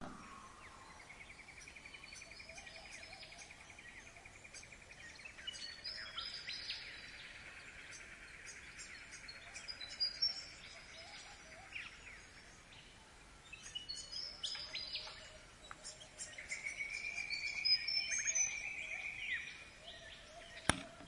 自然 " 鸟类1
标签： 森林 低频 性质 场 - 记录
声道立体声